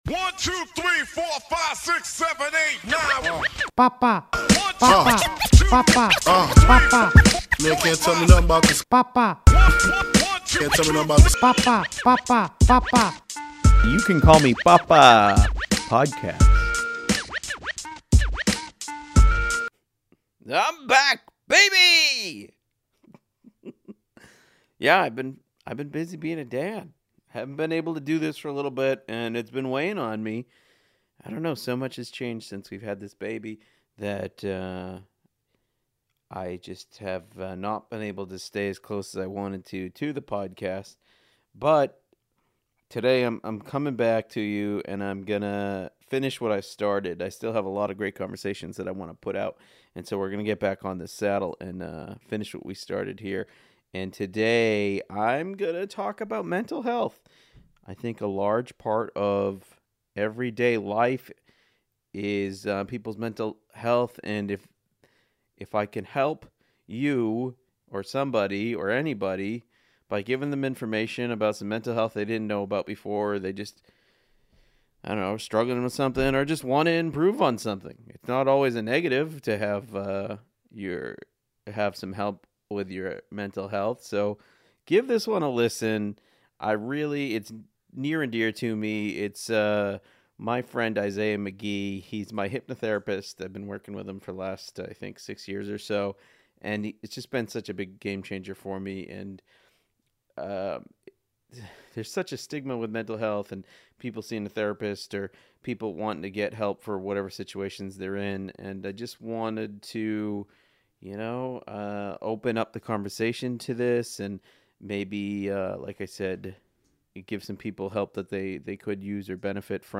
YCCMP-PODCAST-INTERVIEW.mp3